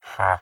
haggle3.ogg